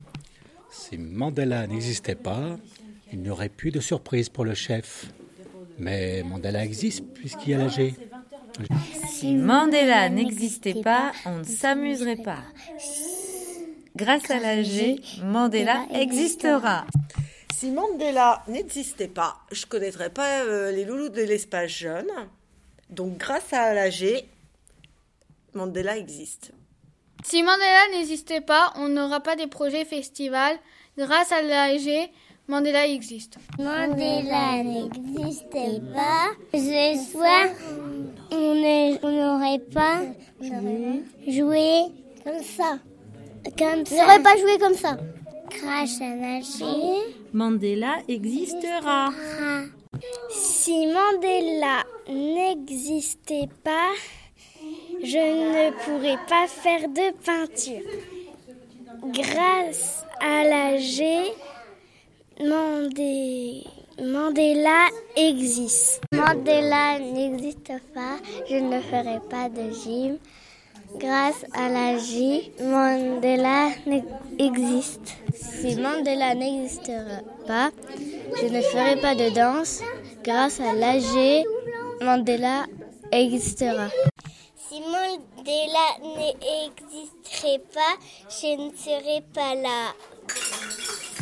Venez nombreux à notre Assemblée Générale jeudi 23 mars à 18h ....et Ecoutez nos usagers à la radio !